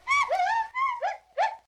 monkey.ogg